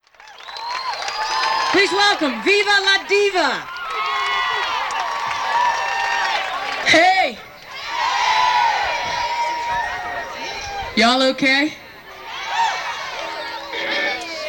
lifeblood: bootlegs: 1994-04-30: earth jam - stone mountain, georgia (alternate recording) (24bit 48khz)
01. talking with the crowd (viva la diva) (0:14)